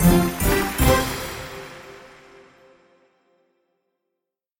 На этой странице собраны звуки викторин — от классических сигналов правильного ответа до зажигательных фанфар.
Звук верных ответов